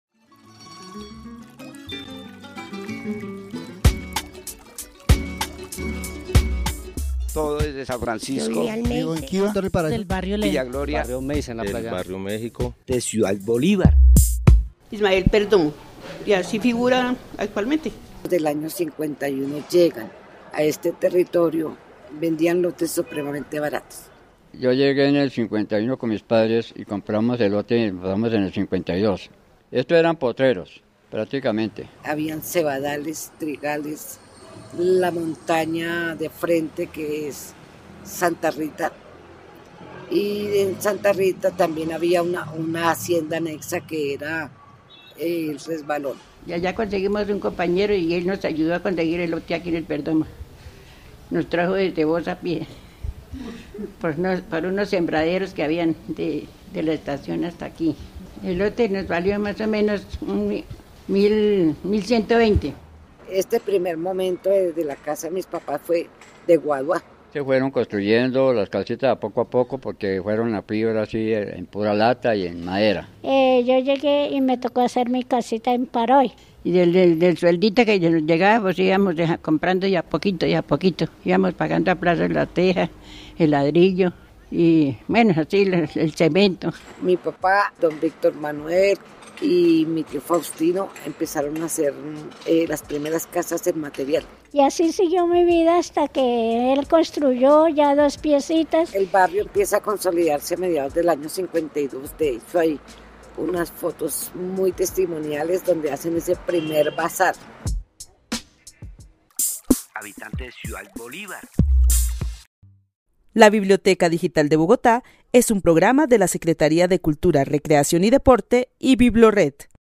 Testimonios de los primeros pobladores del barrio Perdomo sobre las casas patrimoniales, la conformación de los lotes y las primeras obras sociales que tuvieron lugar desde la década de 1950.